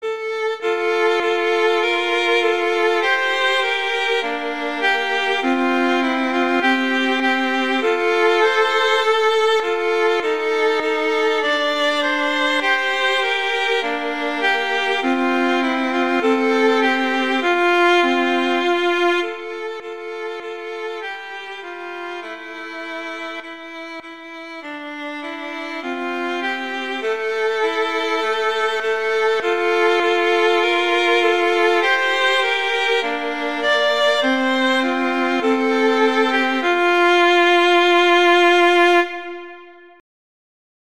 arrangements for two violins
two violins